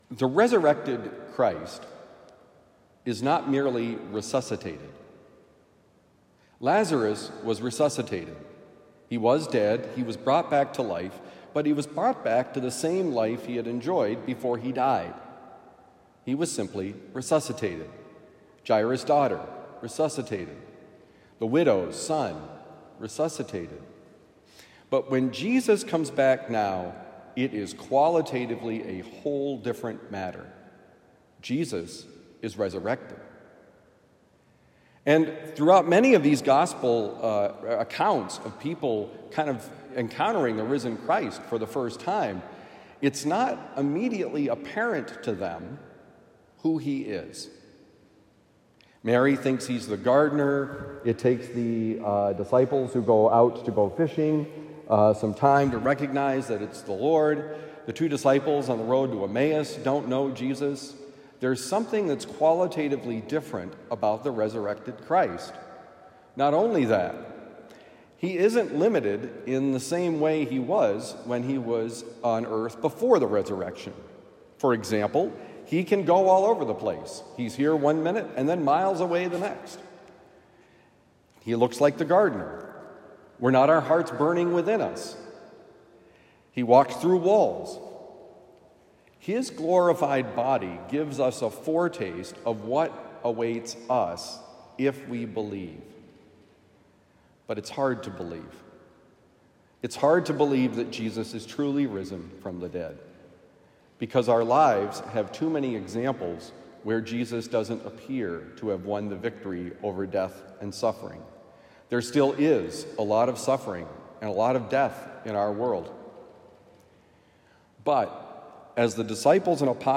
Resurrection not resuscitation: Homily for Tuesday, April 11, 2023
Given at Christian Brothers College High School, Town and Country, Missouri.